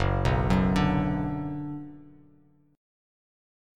F#sus4#5 chord